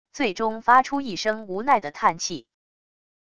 最终发出一声无奈的叹气wav音频